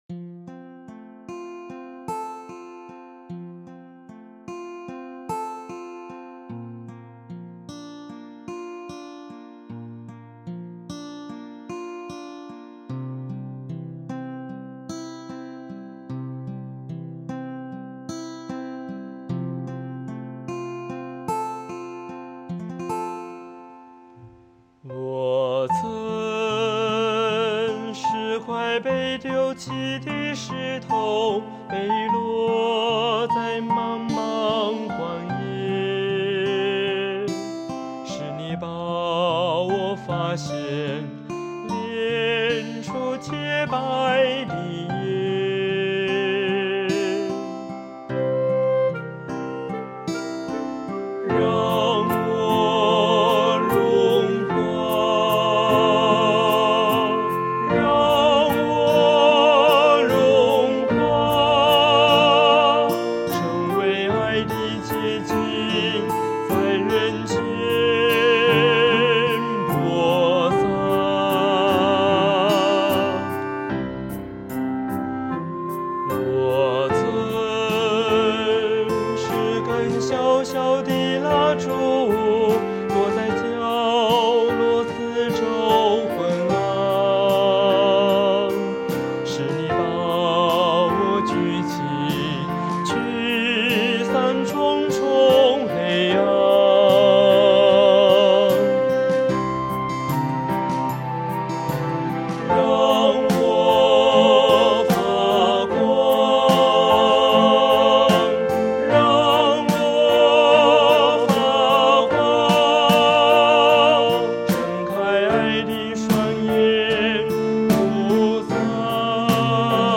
【原创圣歌】
本曲的编曲、旋律、和声等也都是我一人所做！